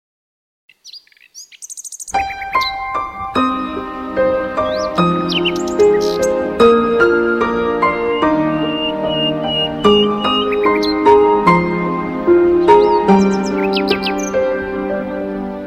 birds-singing.mp3